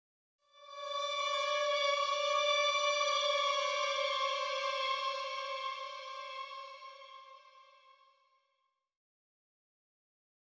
Suspense 2 - Stinger 2.wav